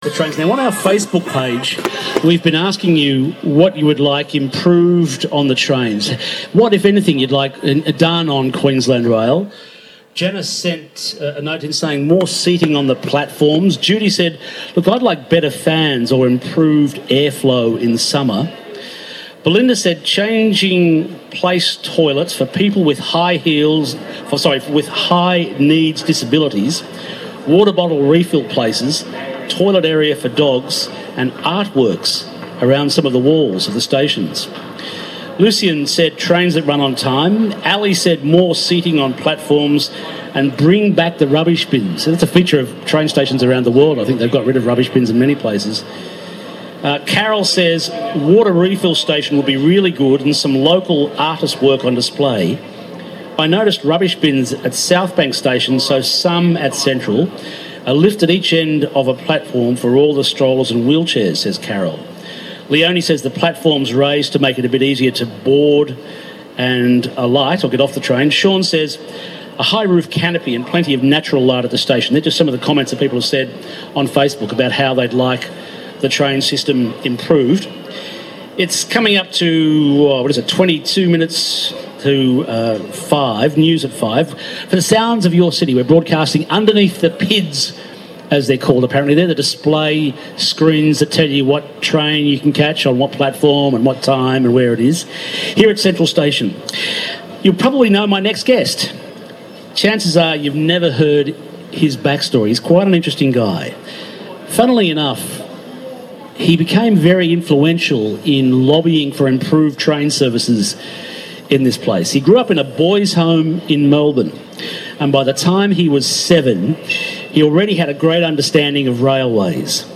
Interview
conducted at Central Station as part of an outside broadcast.